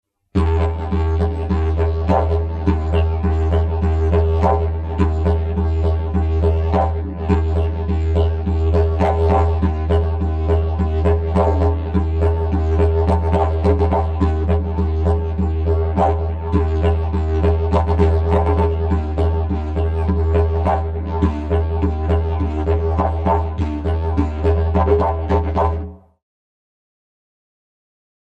Sample n°13 contiene: applicazione dell’urlo su ritmo (è necessario aver appreso almeno una tecnica per comporre ritmi, vedi prossimo capitolo).